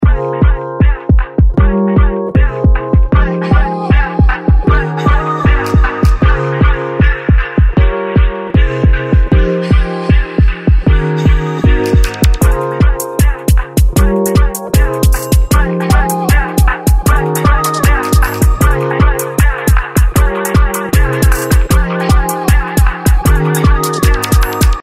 カテゴリーのVOCALSから、ウィスパーボイスを作れる「Whisper BGVs」と、Y2Kサウンドのディレイを再現した「Indie 2000s Vocals」をボーカル成分にかけてみたので、お聴きください。
どちらも薄くかけるだけでも良い効果を得られます。